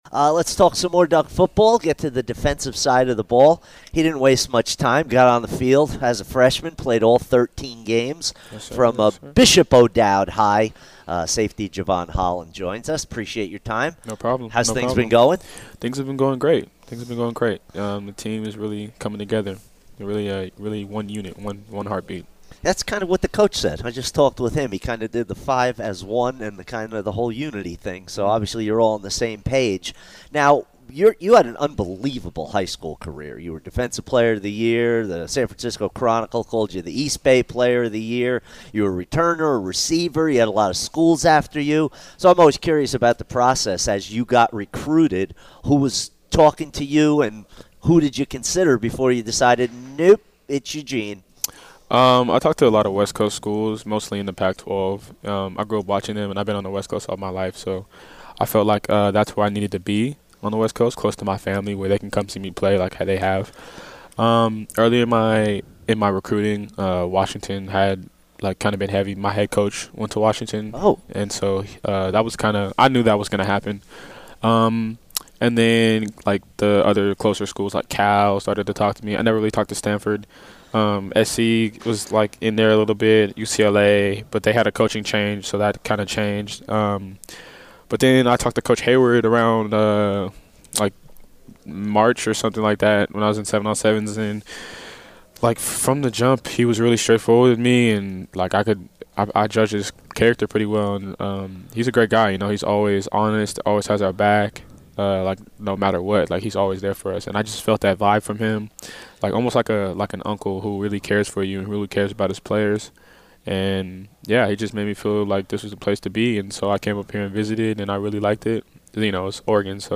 Spots Talk - Jevon Holland Interview 8-2-19